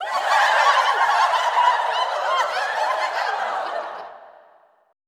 LAUGHTER 3-L.wav